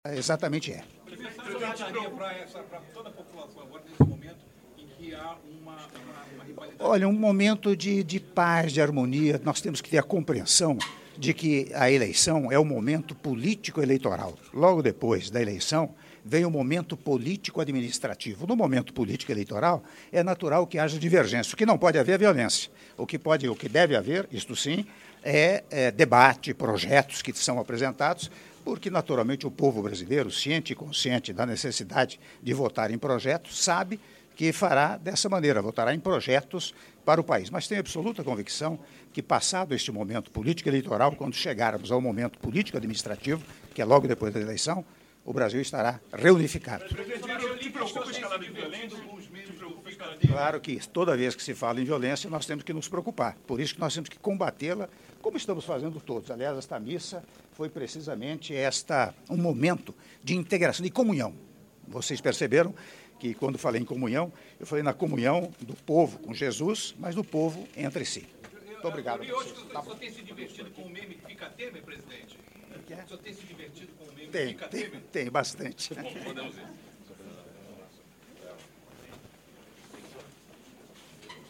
Áudio da entrevista coletiva do senhor presidente da República, Michel Temer, após cerimônia religiosa no Rio de Janeiro - Rio de Janeiro/RJ - (01min21s)